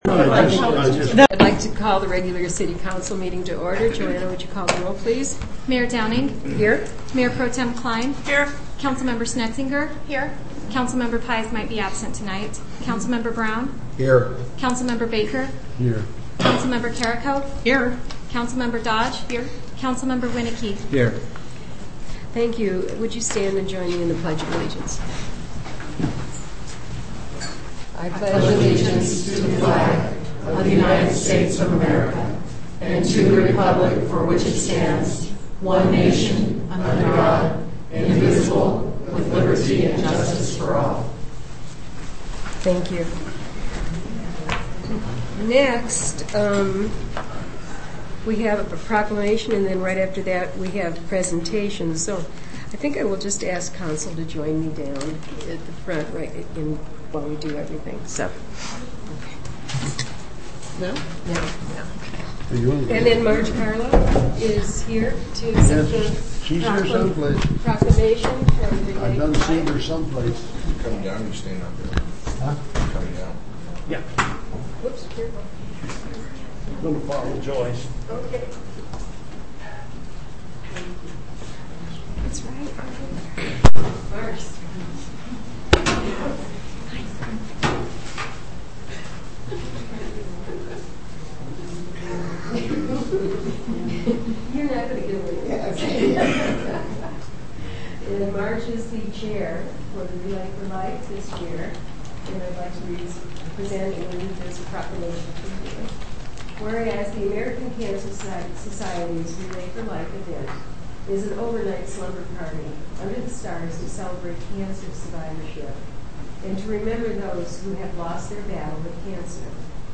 Audio of City Council on 2011-08-25